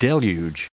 added pronounciation and merriam webster audio